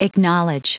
Prononciation du mot acknowledge en anglais (fichier audio)
Prononciation du mot : acknowledge